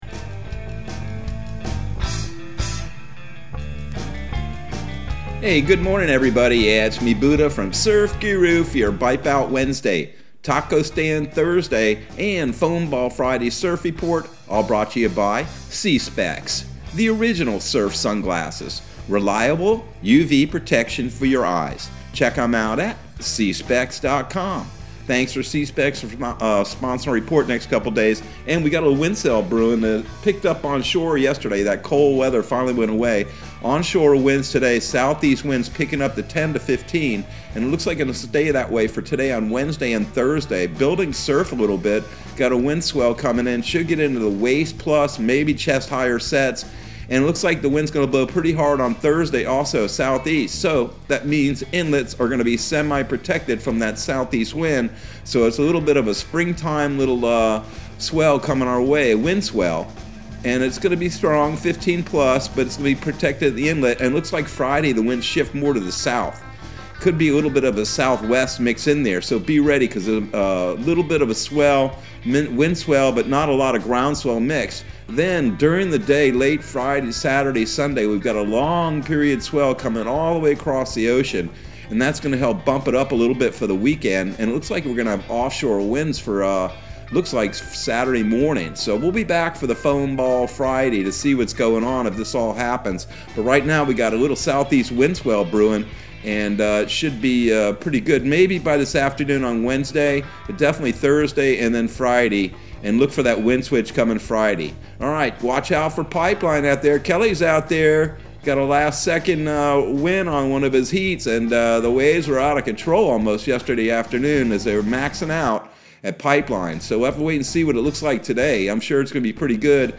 Surf Guru Surf Report and Forecast 02/02/2022 Audio surf report and surf forecast on February 02 for Central Florida and the Southeast.